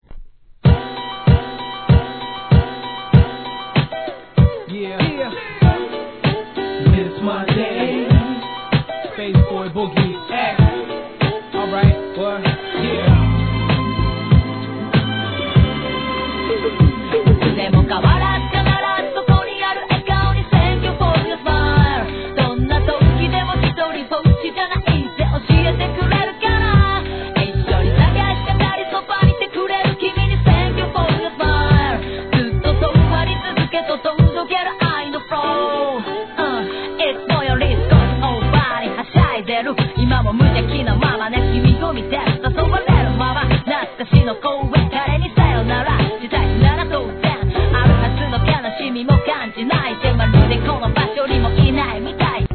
JAPANESE HIP HOP/R&B